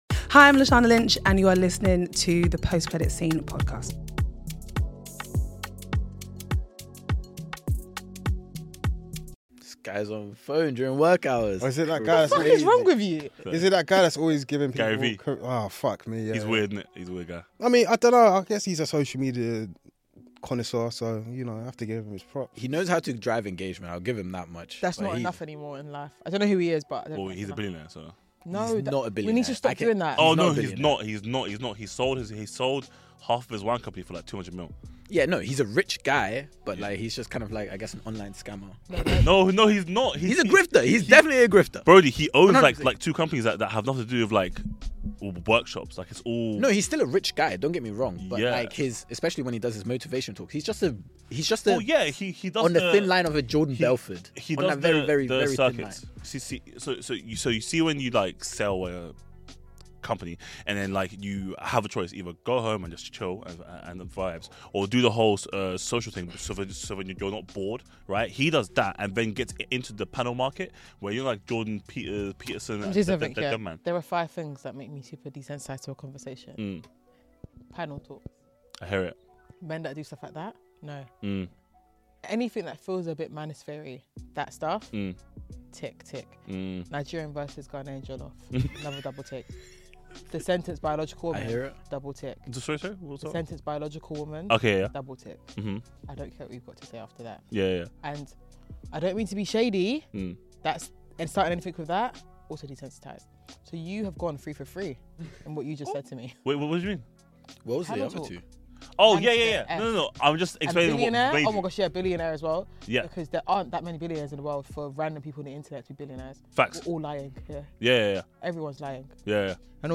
From blockbuster movies to series gems, the hosts bring their unique perspectives, humour, and passion to every episode, making each conversation as entertaining as it is informative.